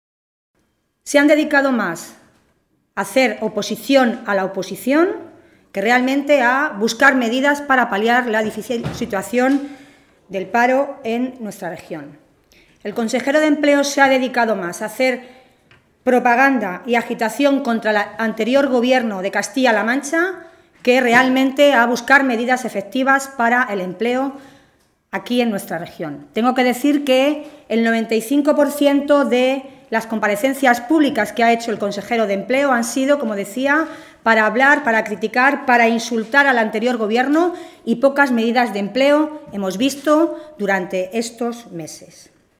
Milagros Tolón, diputada regional del PSOE de Castilla-La Mancha
Cortes de audio de la rueda de prensa